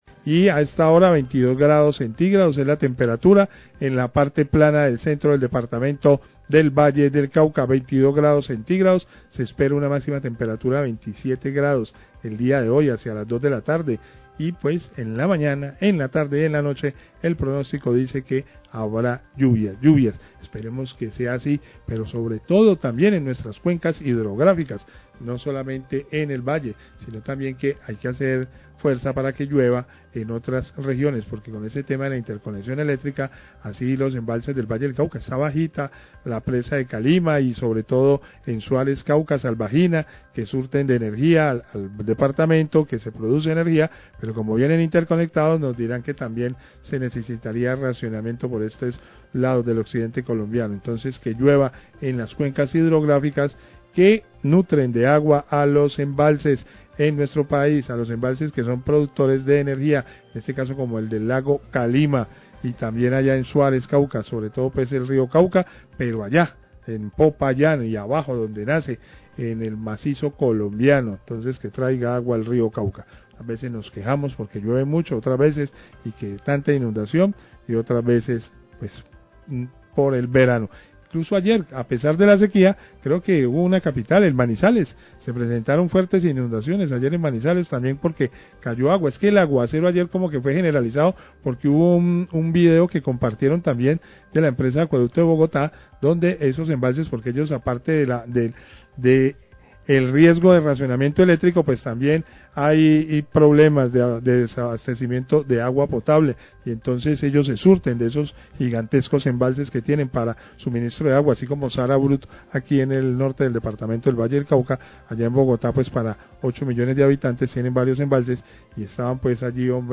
Radio
Periodista habla del nivel de los embalses de la represa de La Salvajina y de la central hidroeléctrica de Calima que se encuentran bajos como efecto de la temporada de verano.